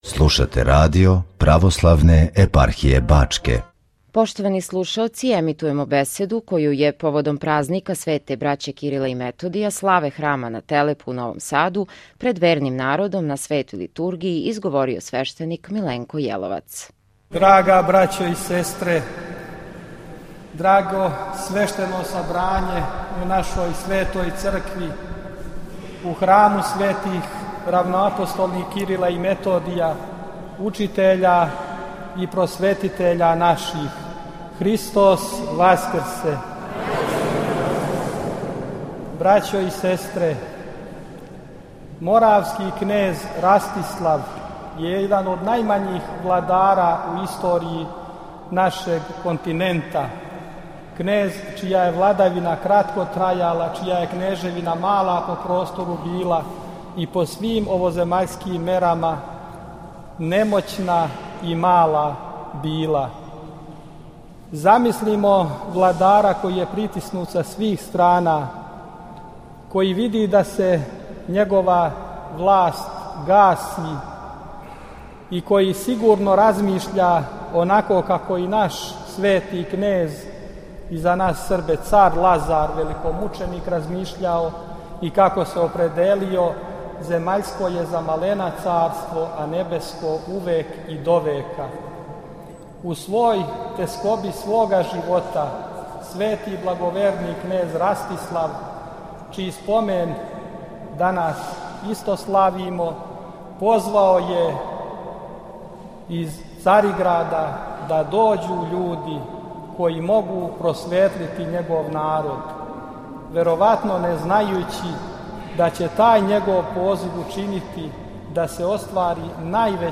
Лимана и Адица прославили су евхаристијским сабрањем које је
Данашње славље благоукрашено је појањем хора студената Богословског факулета